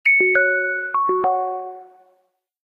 05_Ding_Dong.ogg